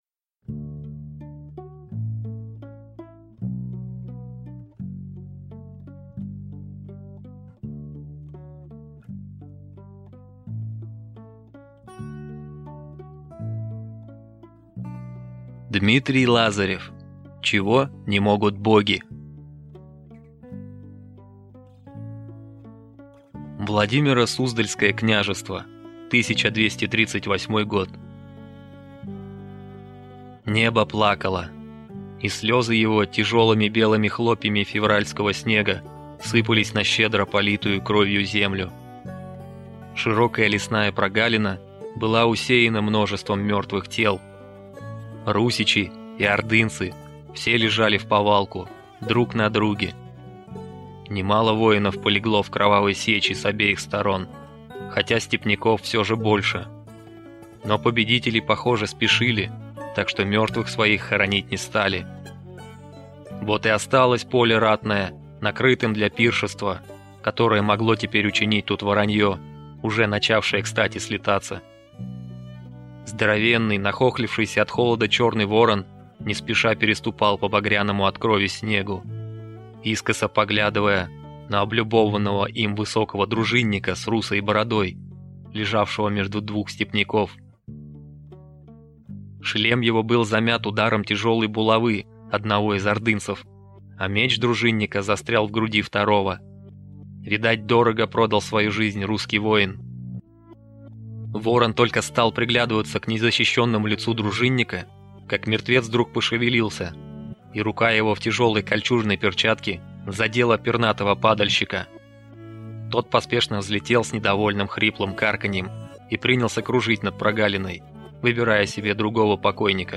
Аудиокнига Чего не могут боги | Библиотека аудиокниг